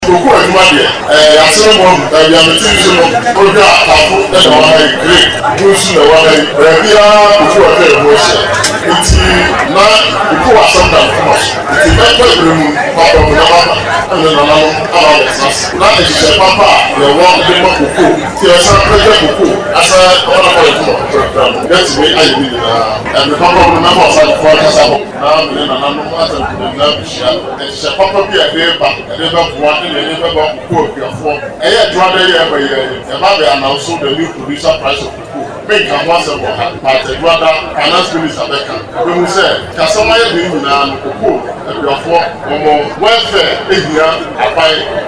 Speaking at the funeral